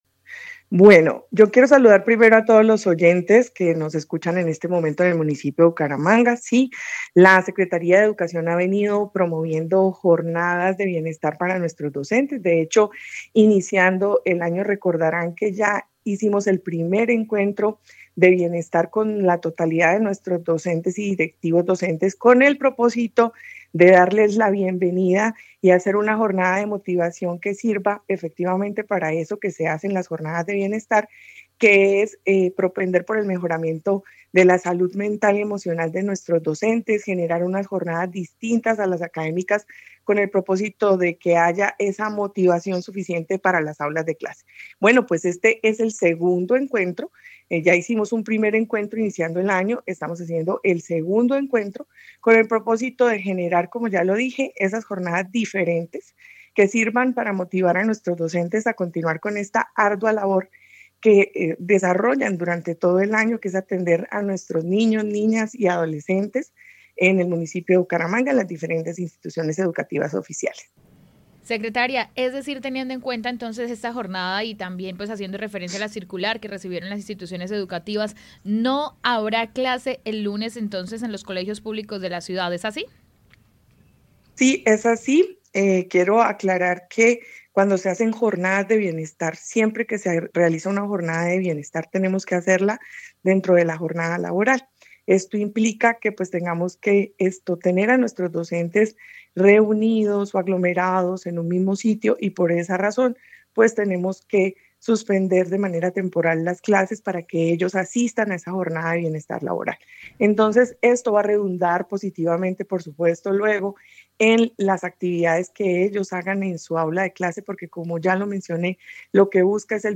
Martha Cecilia Guarín, secretaria de educación de Bucaramanga